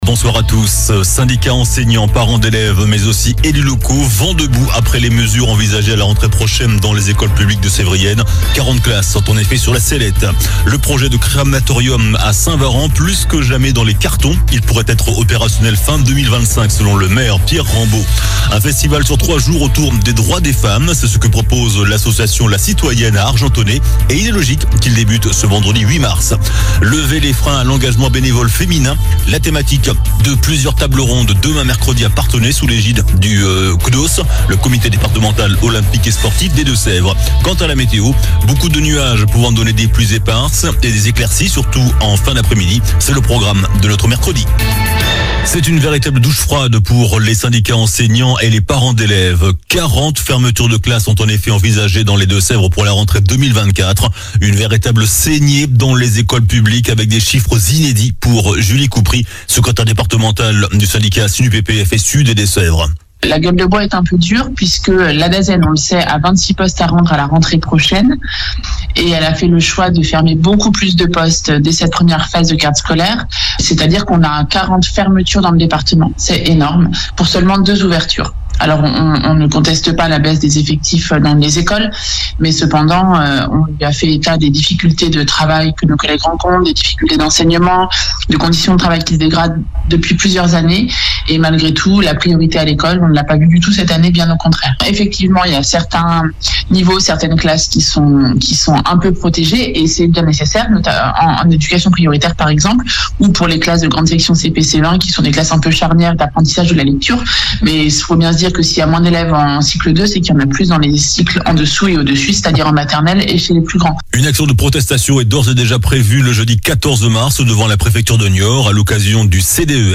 Journal du mardi 5 mars (soir)